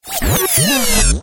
Звуки отключения электричества
Звук из-за которого электроника перестает работать